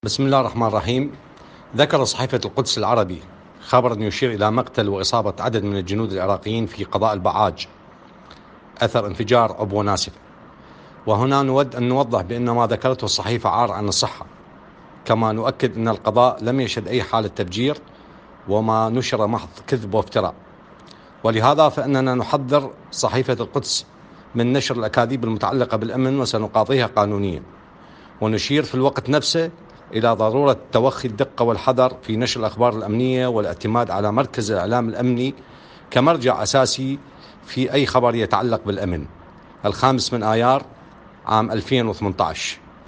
وقال المركز في  تصريح صوتي ا, إن “صحيفة القدس العربي نشرت خبراً يشير الى مقتل واصابة عدد من الجنود العراقيين في قضاء البعاج اثر انفجار عبوة ناسفة”.